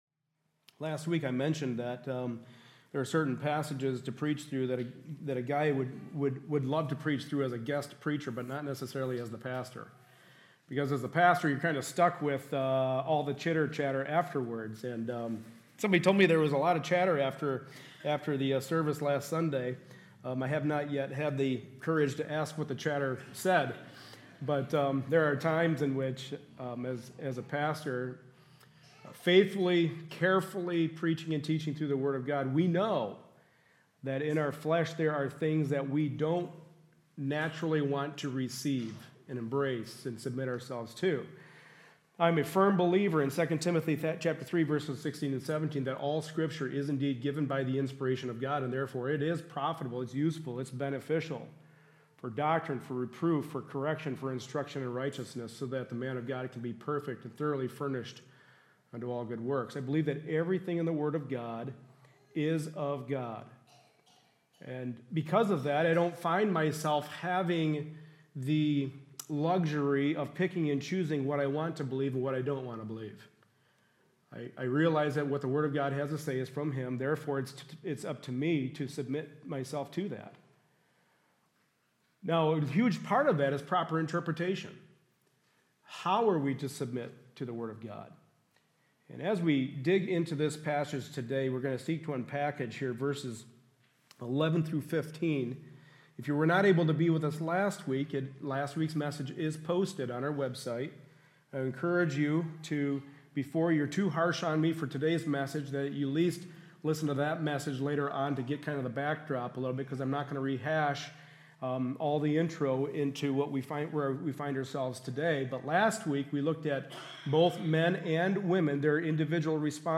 1 Timothy 2:9-15 Service Type: Sunday Morning Service A study in the pastoral epistles.